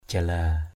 jala.mp3